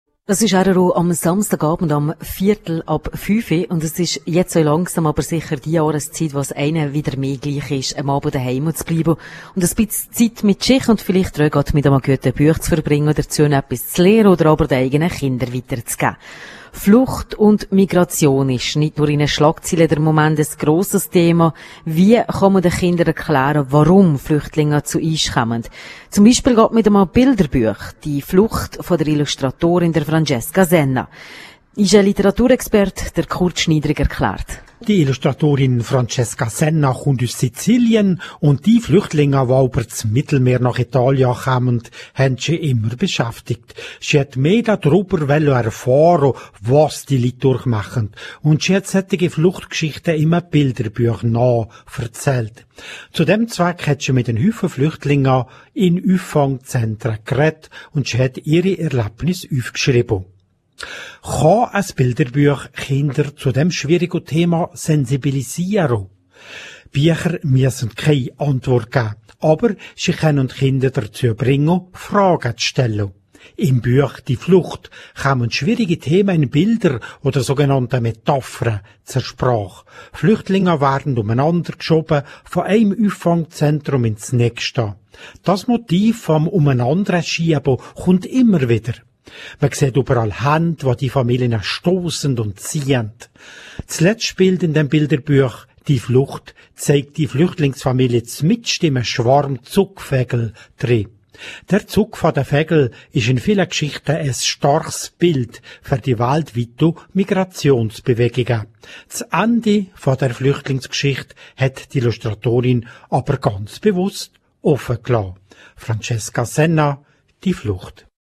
Moderation: Moderation